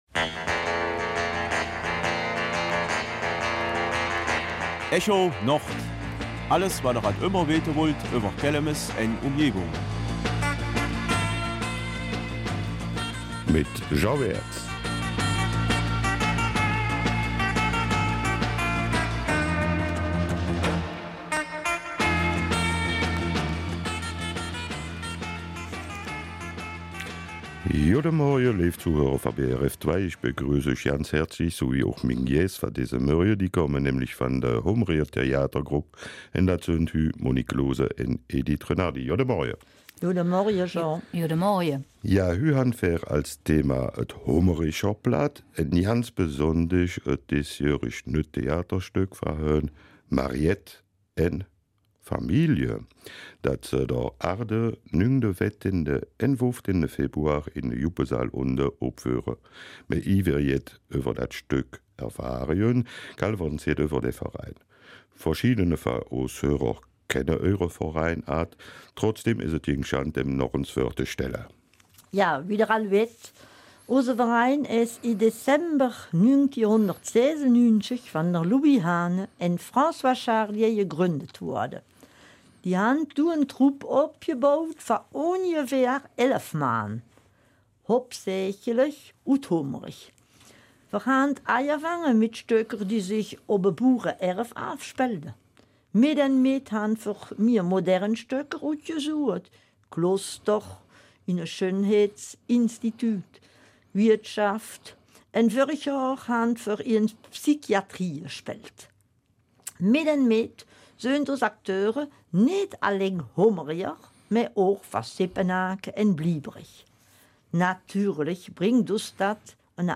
Kelmiser Mundart: Theater auf Platt in Hombourg